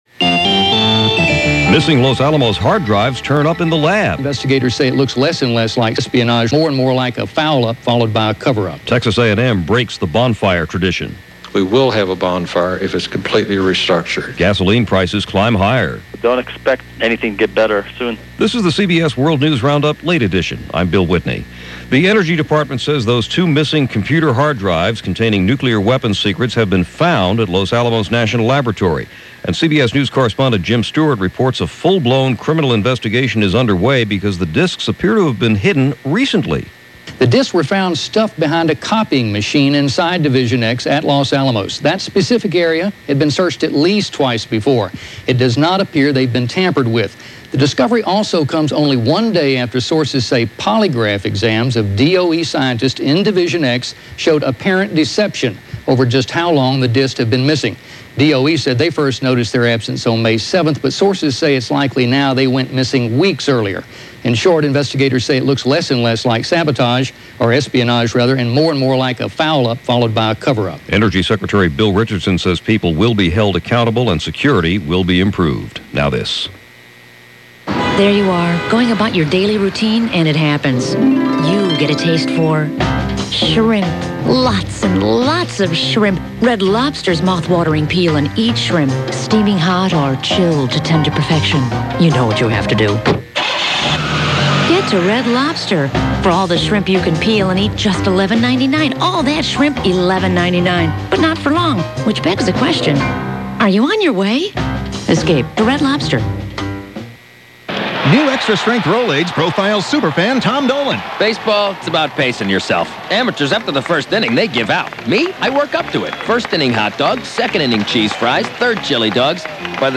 All that, and a lot more for this June 16th in 2000 as presented by The CBS World News Roundup.